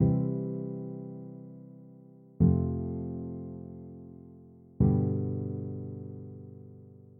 To learn this feeling, play the following chord progression C-F-G.  It should sound unfinished.
C-F-G
I-IV-V.mp3